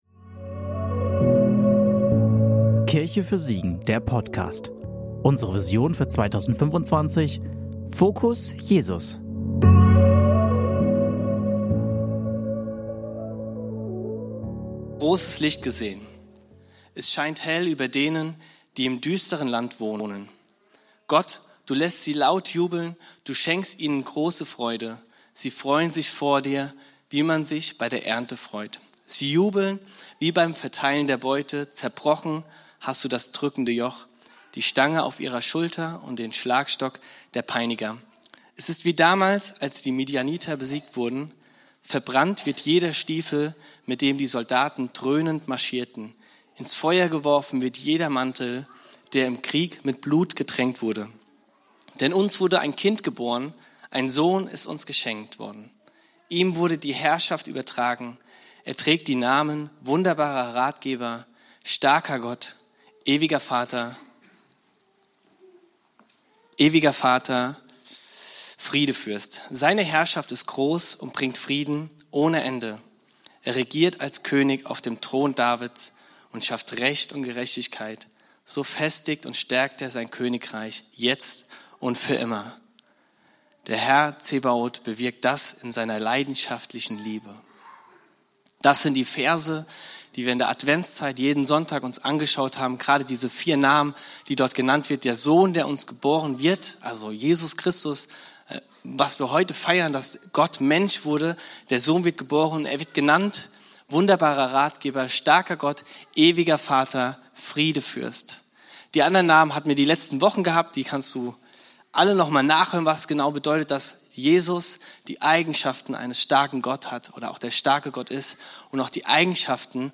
Der letzte Teil der Predigtserie "Frieden auf Erden".
Predigt vom 24.12.2025 in der Kirche für Siegen